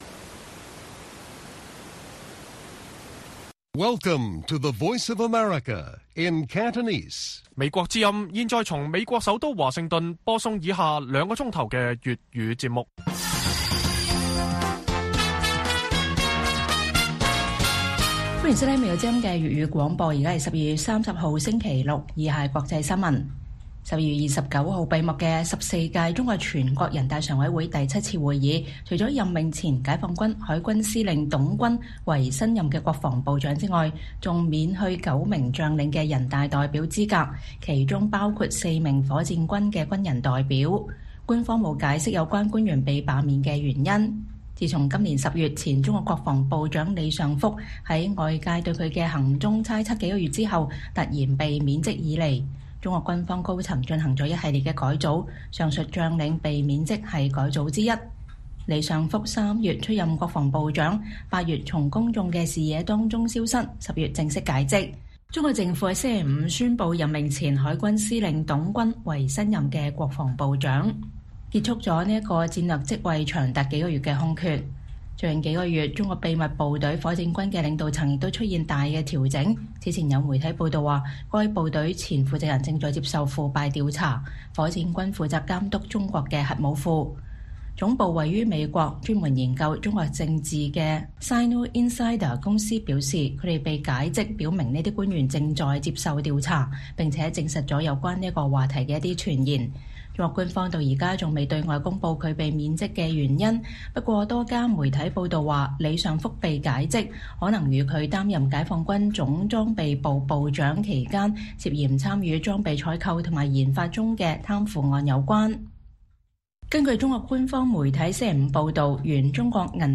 粵語新聞 晚上9-10點：解放軍9名將領被罷免中國全國人大代表資格